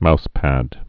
(mouspăd)